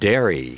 Prononciation du mot dairy en anglais (fichier audio)
Prononciation du mot : dairy